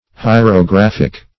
Search Result for " hierographic" : The Collaborative International Dictionary of English v.0.48: Hierographic \Hi`er*o*graph"ic\, Hierographical \Hi`er*o*graph"ic*al\, a. [L. hierographicus, Gr.
hierographic.mp3